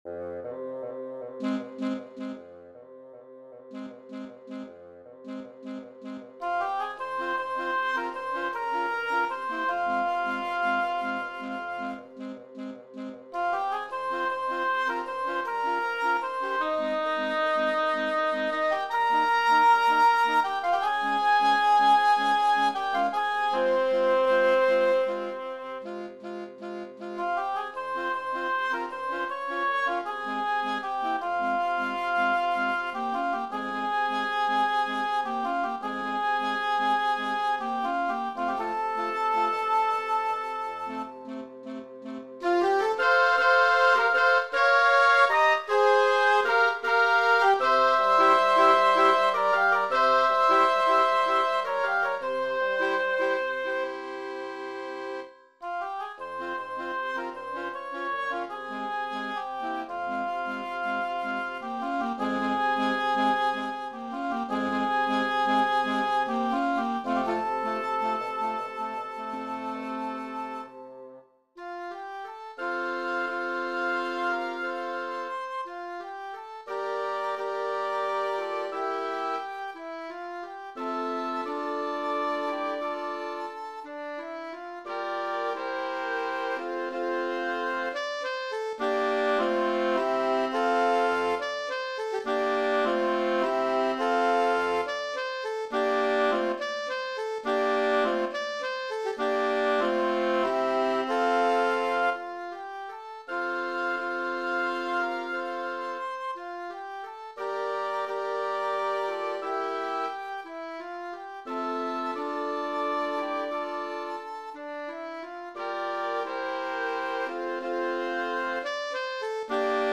Voicing: 5 Woodwinds